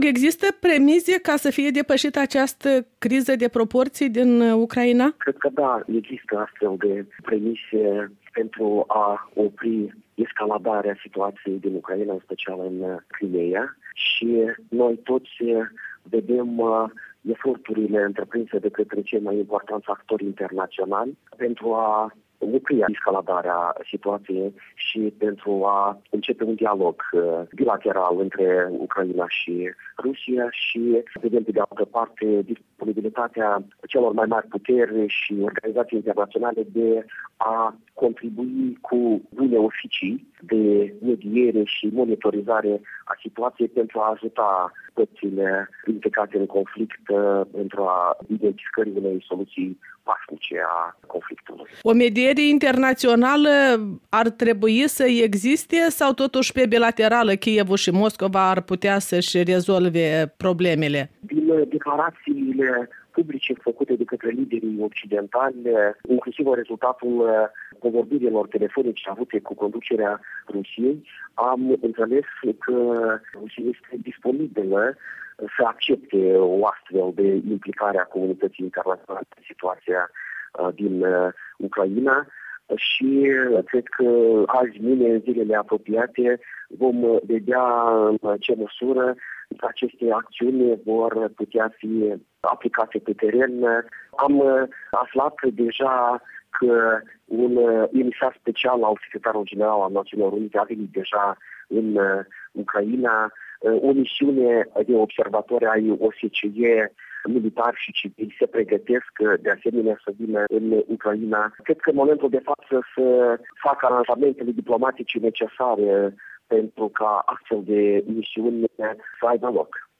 Interviu cu ambasadorul Ion Stăvilă